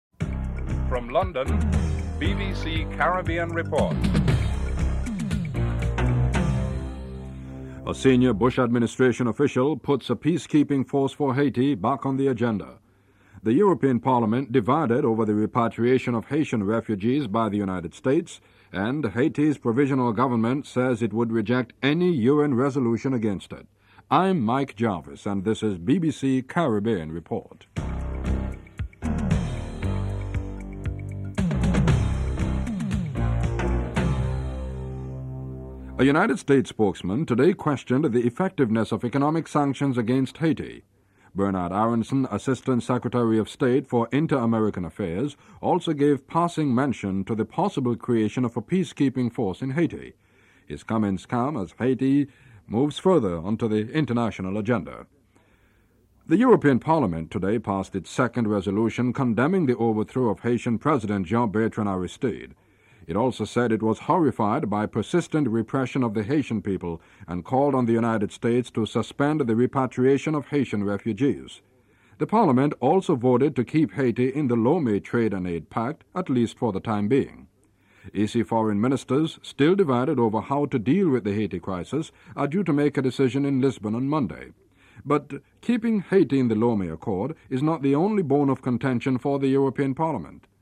1. Headlines (00:00-00:34)
Interview with Jamaica's UN embassador Herbert Walker who says despite restrictions on U.N Security Council's involvement in the crisis members were becoming concerned about the situation (03:56-06:08)